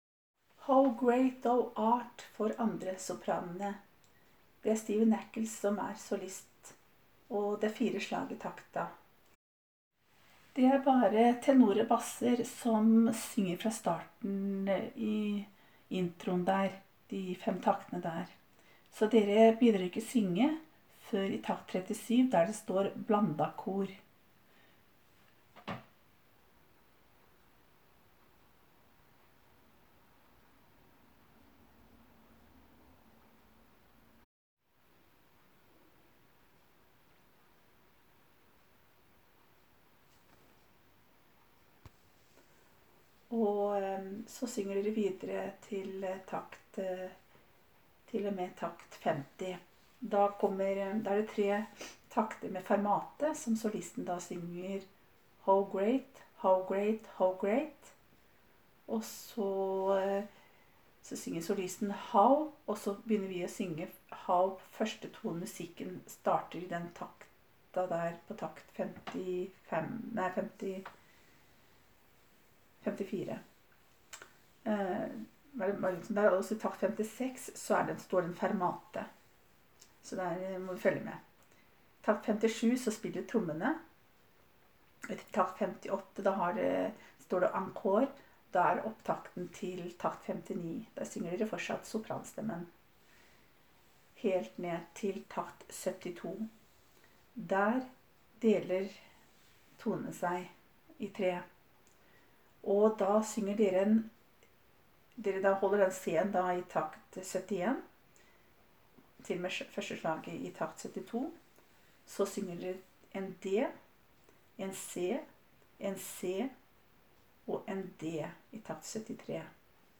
1.Sopran. How great thou art (ny 14.8.19):